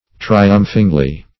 -- Tri"umph*ing*ly , adv.